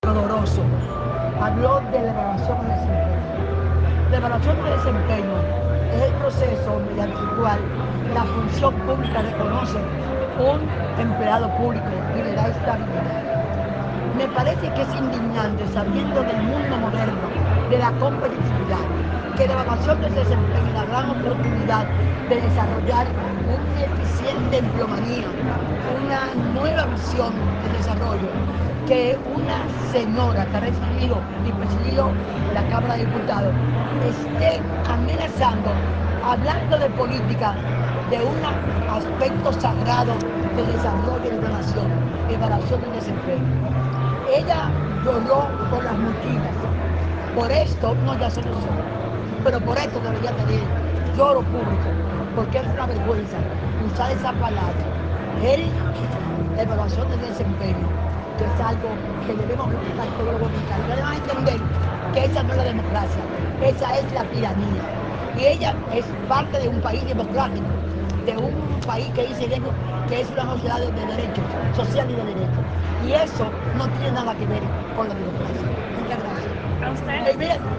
“Me parece indignante, doloroso, que una señora que ha presidido la Cámara de Diputados esté amenazando, hablando de política, de un aspecto sagrado del desarrollo de una nación. Ella lloró por las mochilas, pero por esto debería también hacer lloro público, porque es una vergüenza, eso no es la democracia, es la tiranía” , dijo Ortiz Bosch durante la presentación del gabinete presidencial de Luis Abinader.